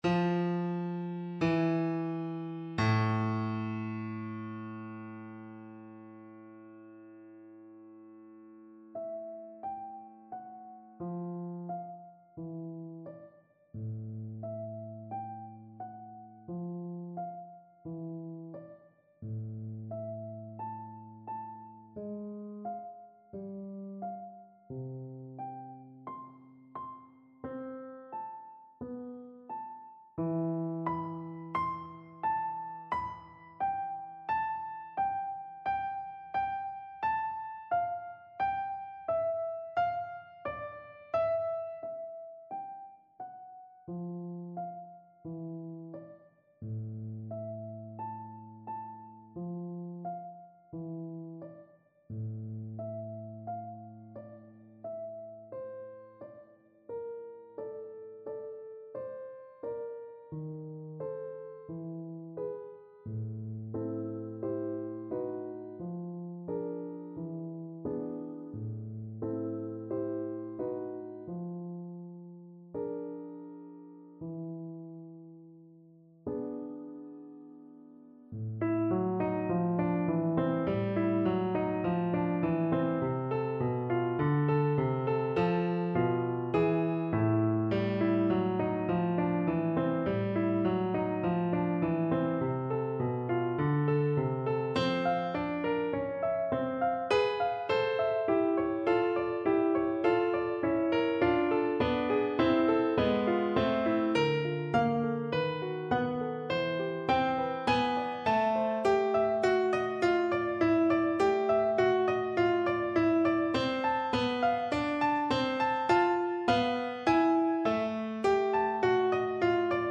Free Sheet music for Orchestral Percussion
Marimba
4/4 (View more 4/4 Music)
A minor (Sounding Pitch) (View more A minor Music for Percussion )
Lento